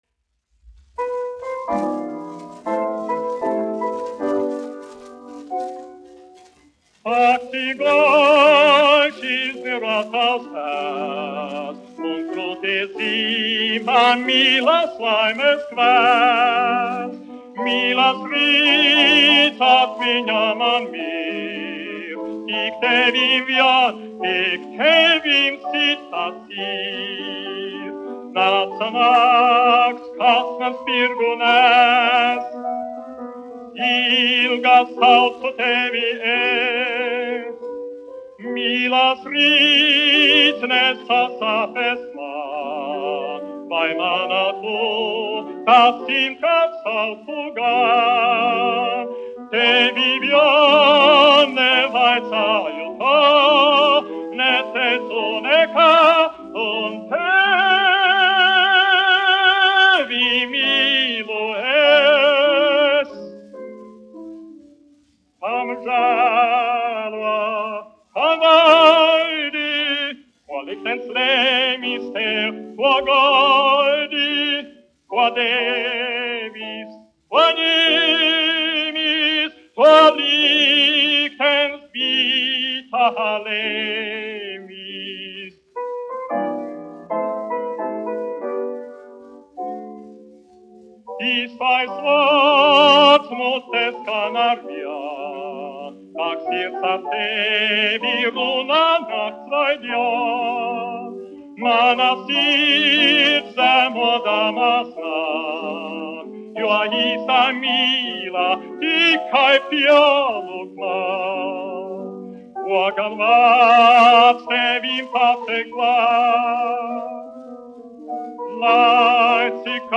1 skpl. : analogs, 78 apgr/min, mono ; 25 cm
Dziesmas (augsta balss) ar klavierēm
Skaņuplate